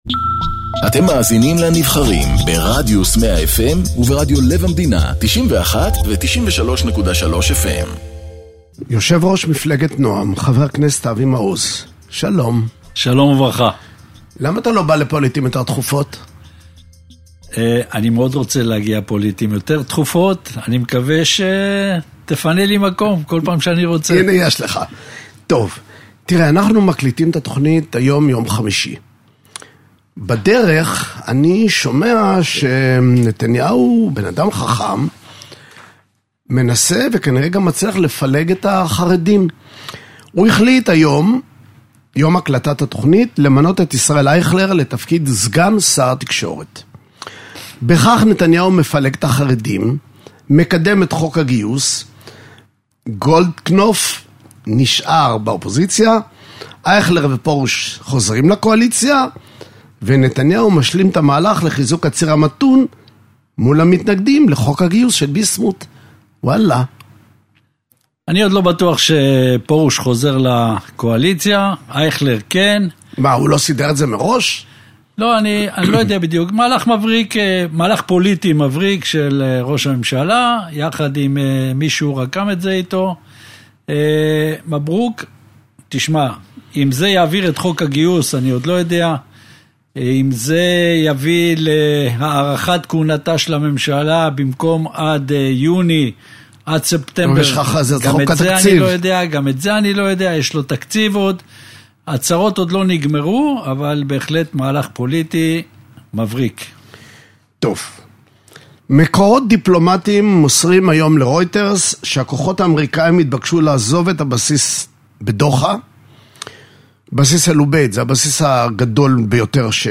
מראיין את יו"ר מפלגת נועם, חבר הכנסת אבי מעוז